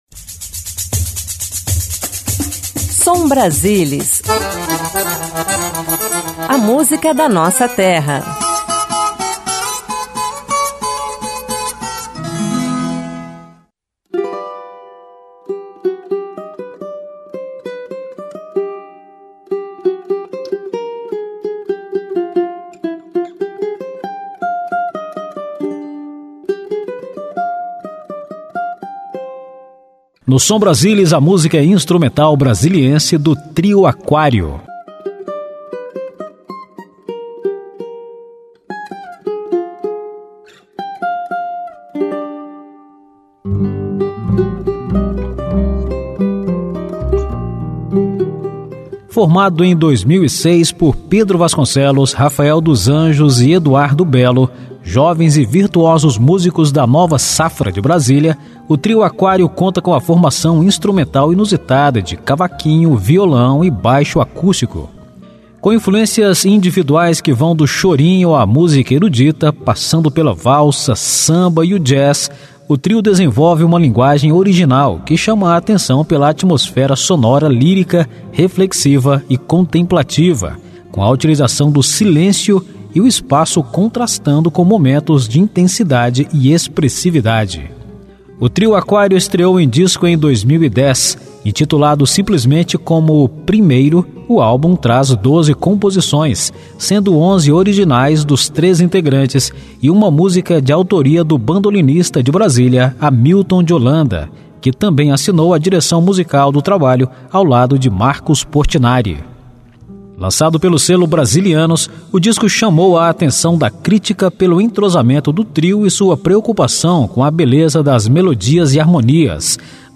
Música instrumental
Choro Jazz